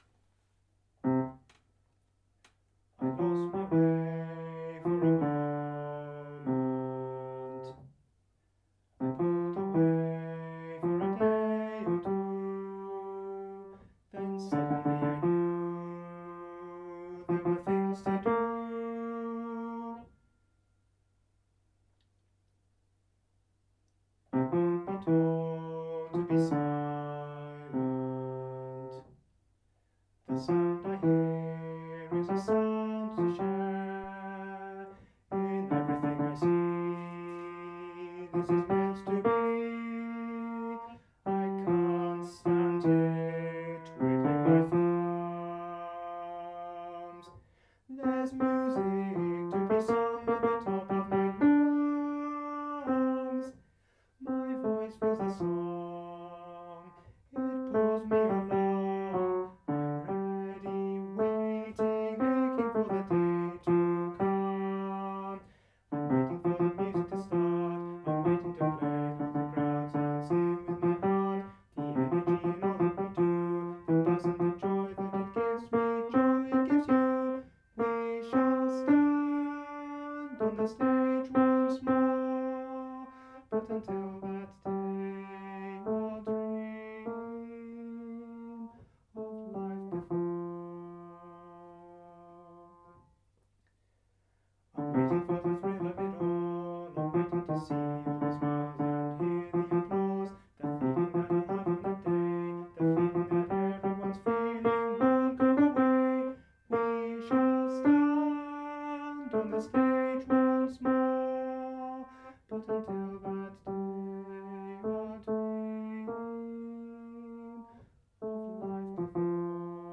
Bass track for practice (ditto)
bass.mp3